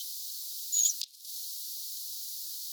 mika_laji_lintutornista_aanitetty.mp3